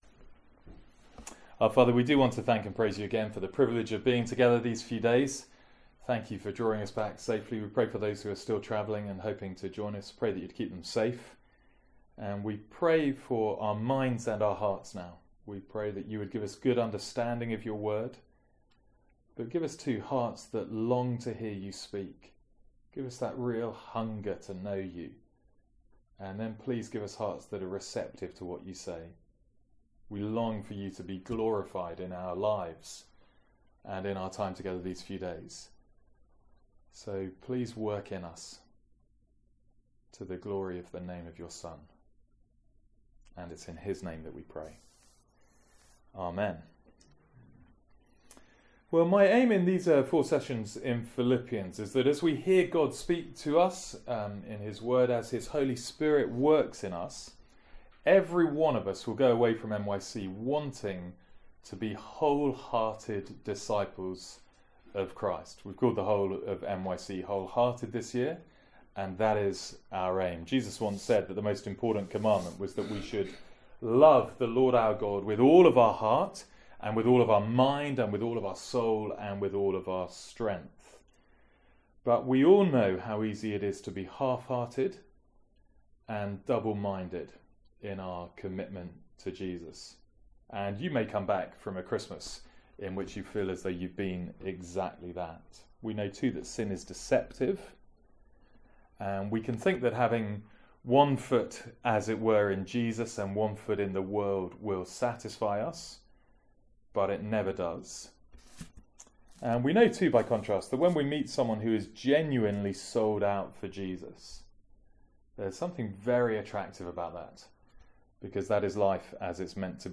Talk 1 from MYC15.